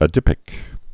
(ə-dĭpĭk)